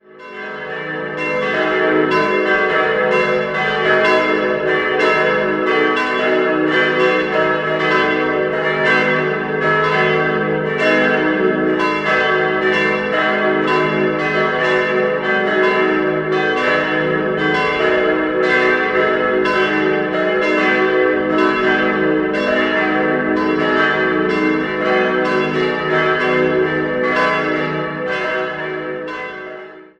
Idealquartett: dis'-fis'-gis'-h' Die Glocken wurden 1955 von Rincker in Sinn gegossen.